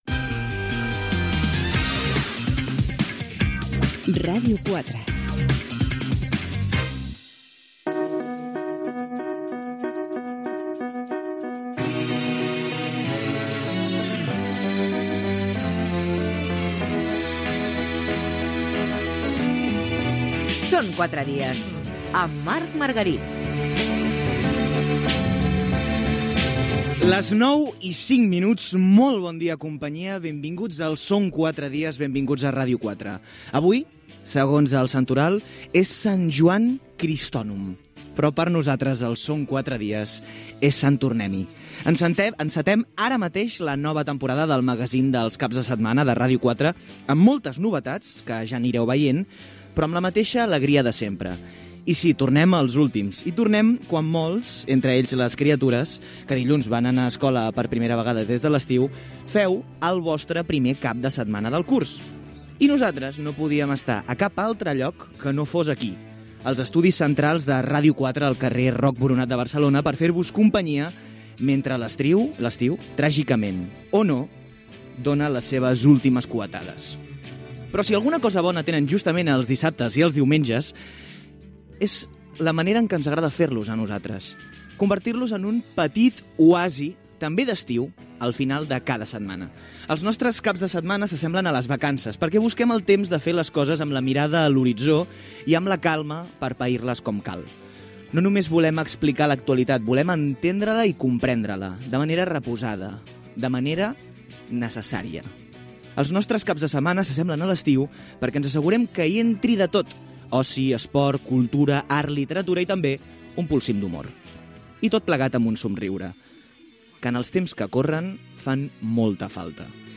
Indicatiu de la ràdio, careta, presentació del primer programa de la vuitena temporada del programa. Els caps de setmana i el compromís del programa.
Entreteniment
FM